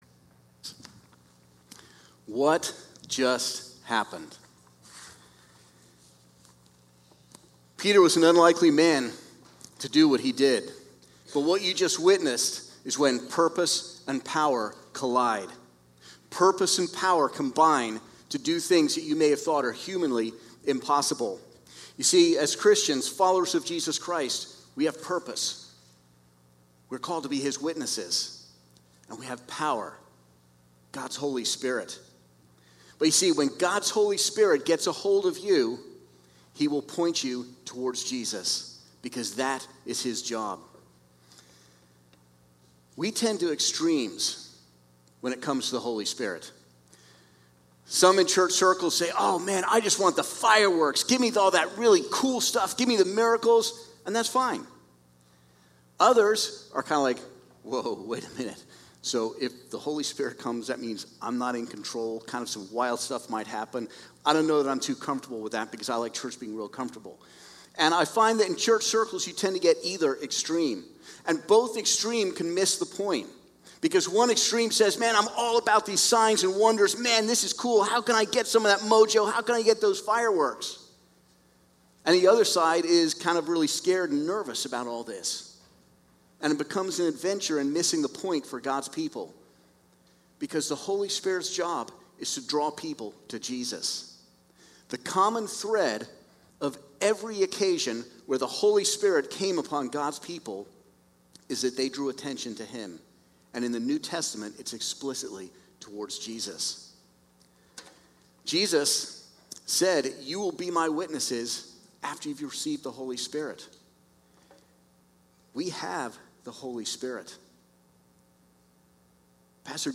10:30 Service
Sermon